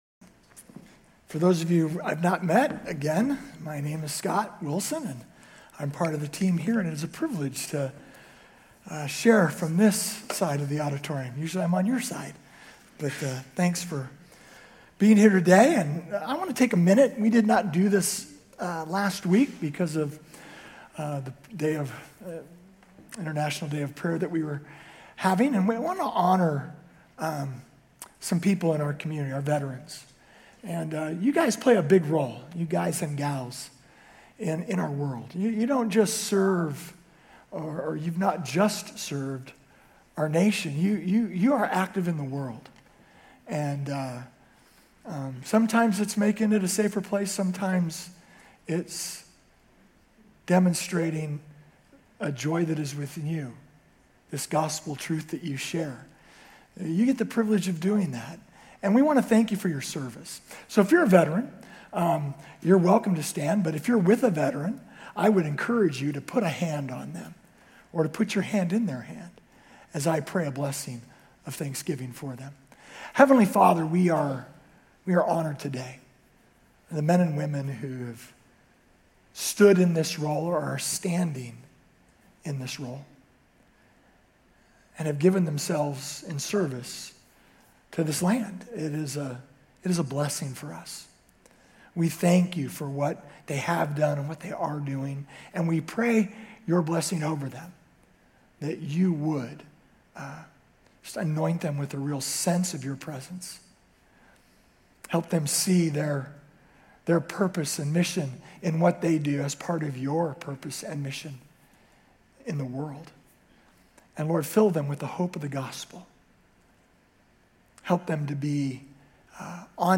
Grace Community Church University Blvd Campus Sermons 11_16 University Blvd Campus Nov 17 2025 | 00:36:34 1x 00:00 / 00:36:34 Subscribe Share RSS Feed Share Link Embed